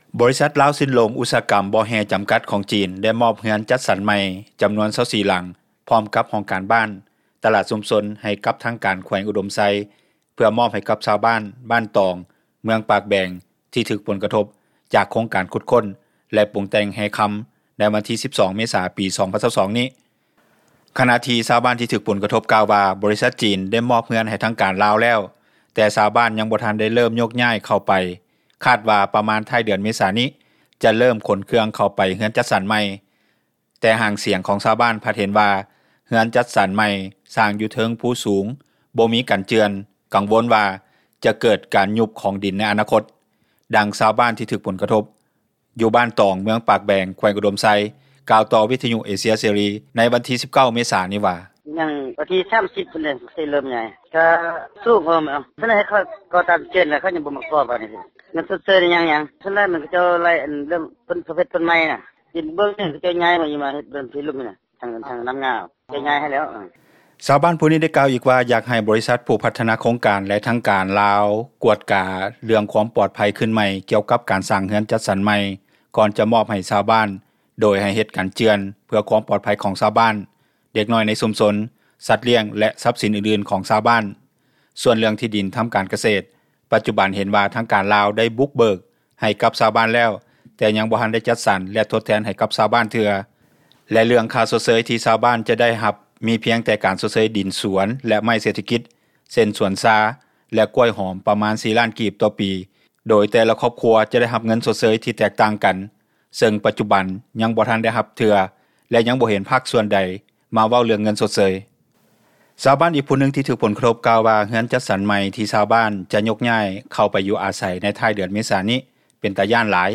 ດັ່ງຊາວບ້ານທີ່ຖືກຜົລກະທົບ ຢູ່ບ້ານຕອງ ເມືອງປາກແບງ ແຂວງອຸດົມໄຊກ່າວ ຕໍ່ວິທຍຸເອເຊັຽເສຣີ ໃນວັນທີ 19 ເມສານີ້ວ່າ:
ດັ່ງເຈົ້າໜ້າທີ່ ຜແນກຊັພຍາກອນທັມມະຊາດ ແລະສິ່ງແວດລ້ອມ ແຂວງອຸດົມໄຊ ທ່ານນຶ່ງກ່າວຕໍ່ວິທຍຸເອເຊັຽເສຣີ ໃນວັນທີ 19 ເມສານີ້ວ່າ: